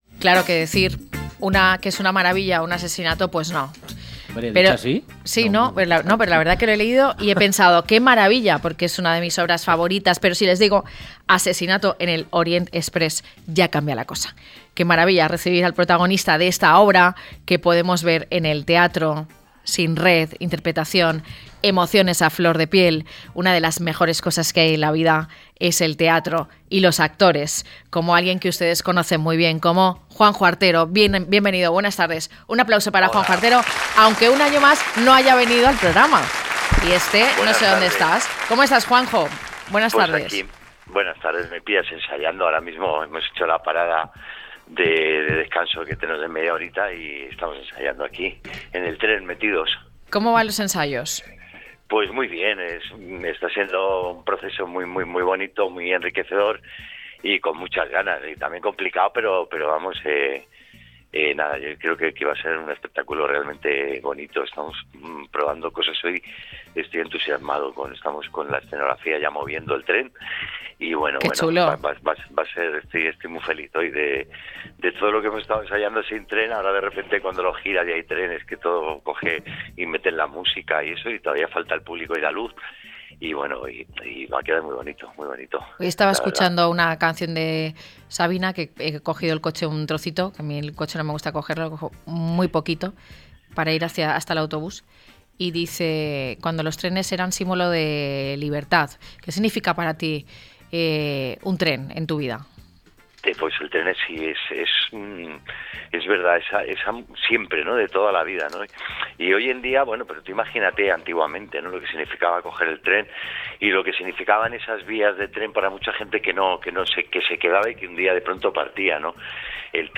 ASESINATO EN EL ORIENT EXPRESS LLEGA AL FLUMEN Hablamos con Juanjo Artero, quien encabeza Asesinato en el Orient Express, la nueva producción de Espectáculos Pinkerton que se estrenará el próximo jueves 19 de diciembre en el Teatro Flumen.